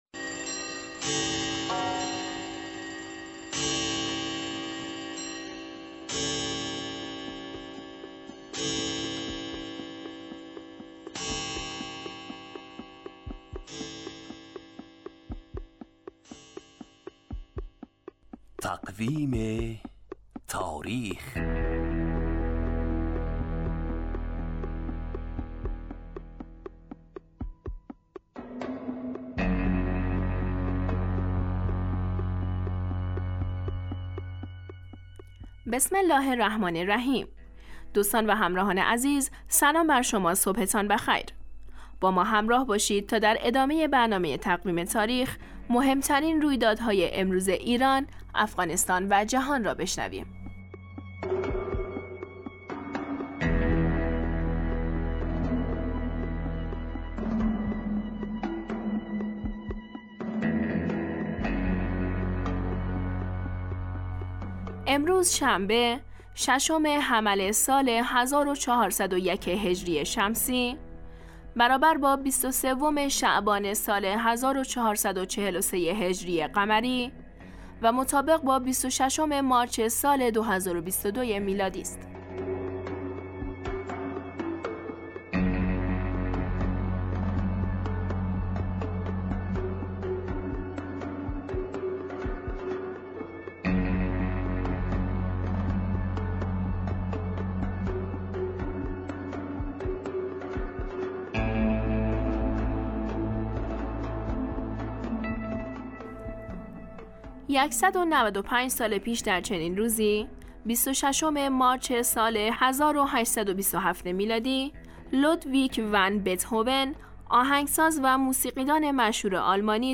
برنامه تقویم تاریخ هرروز ساعت 7:10 دقیقه به وقت افغانستان پخش میشود.